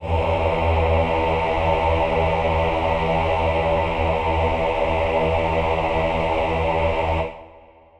Choir Piano
E2.wav